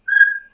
bip_02.mp3.mp3